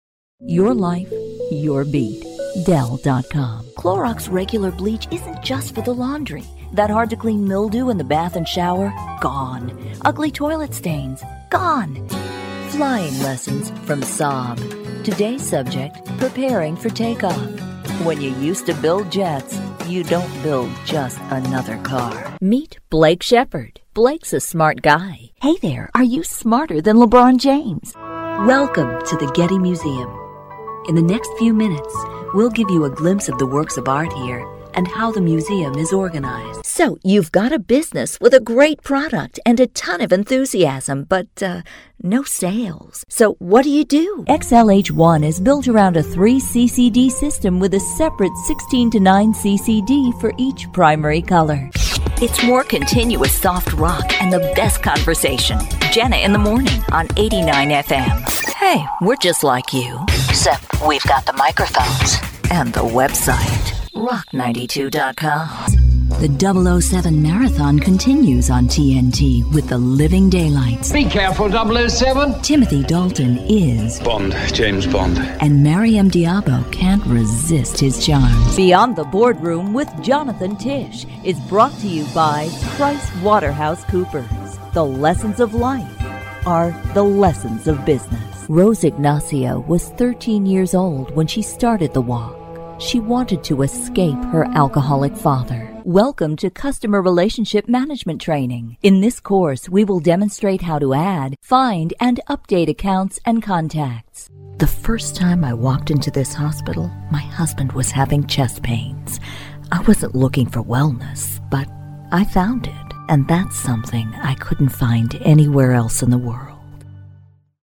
Demo
Adult, Mature Adult
Has Own Studio
Warm, knowledgeable, real, inviting, conversational, believable, friendly, genuine, upbeat, upscale, smooth, fun, classy, confident, authoritative, professional, silky, sultry, energetic, dynamic, dramatic, playful, educated, humorous, sexy, smart, sassy, bubbly, classic!
Lots of characters/ages/accents, all with emotional truth.
british rp | natural